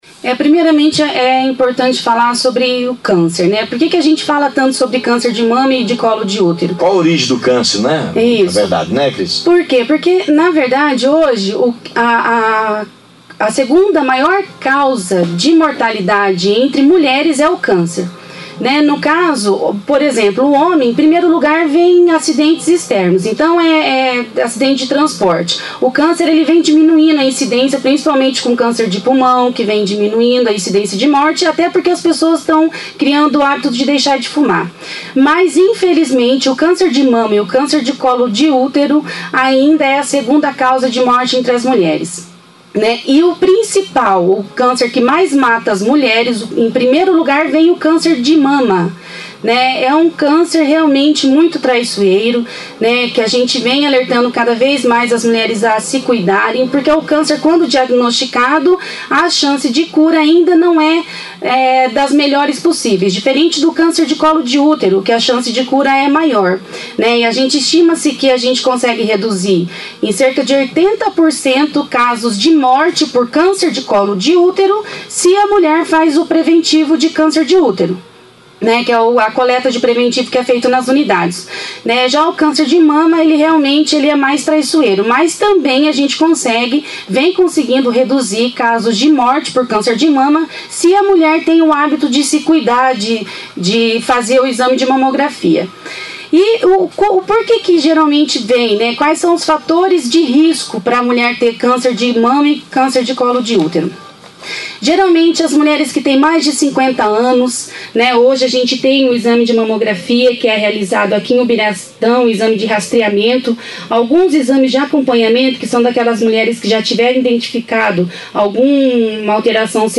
No Dia Mundial de Combate ao Câncer, segunda-feira (8), a secretária municipal de Saúde, Cristiane Pantaleão concedeu uma entrevista sobre o assunto. Cristiane falou que o câncer é a segunda maior causa da mortalidade feminina, em especial o câncer de mama.